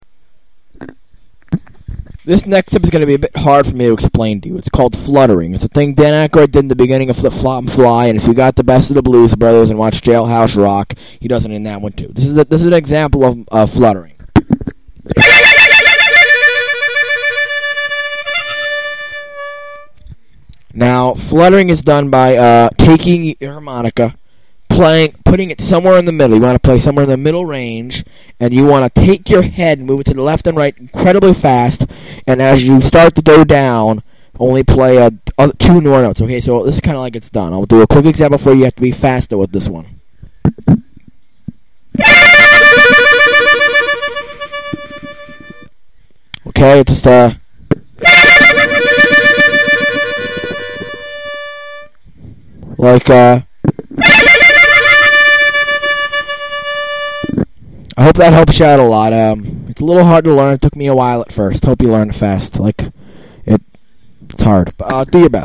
Fluttering: fluttering is when blowing in while playing single notes and moving your head left and right Its kind of like what Akroyd did in the very beginning of the harp solo in Flip Flop n’ Fly.
Demo Clip
fluttering.mp3